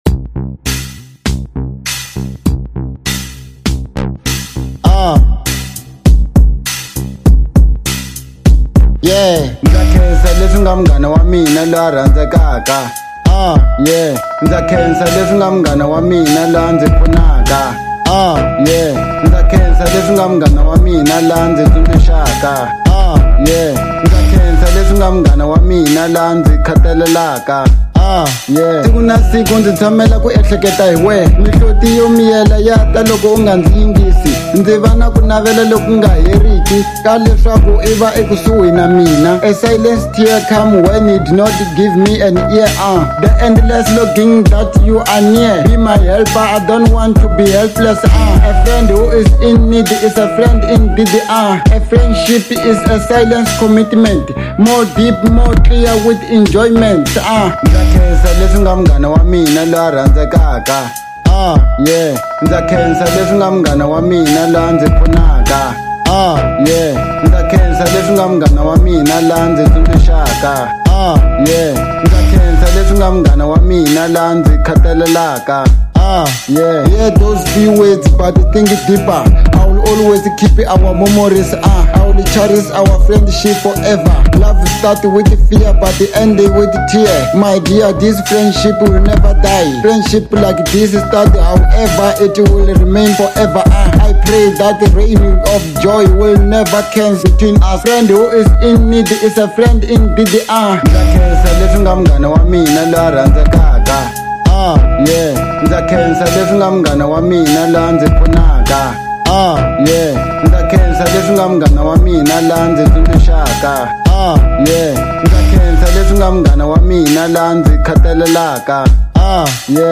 02:42 Genre : Hip Hop Size